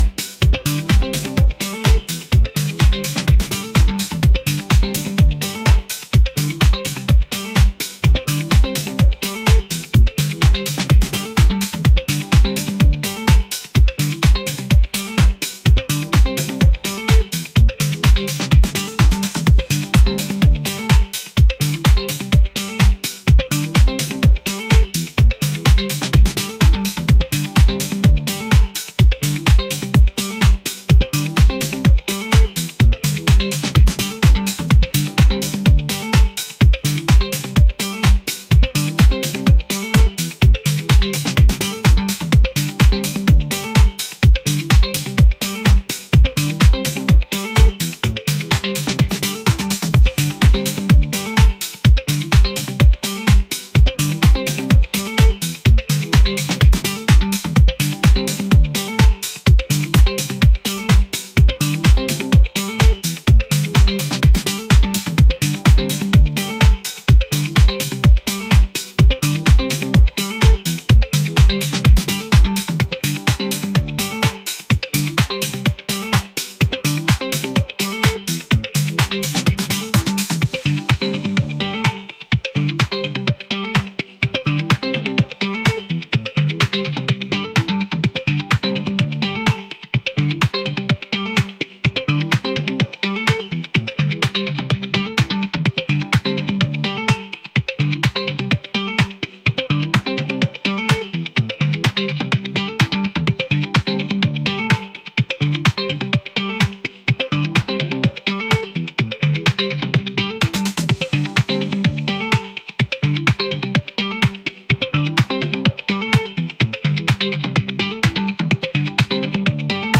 energetic | funky